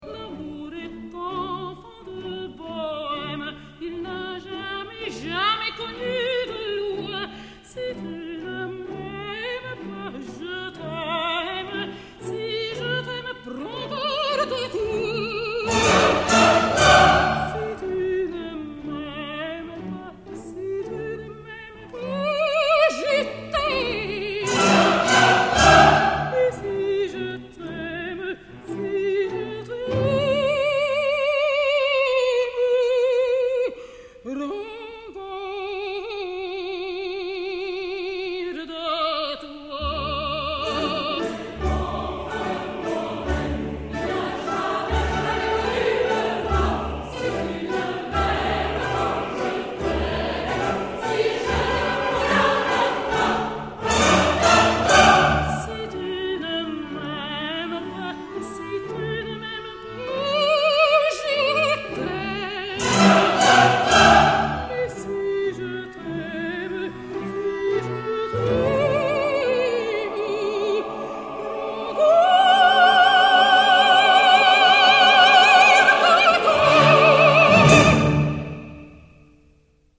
Parigi presso la Salle Wagram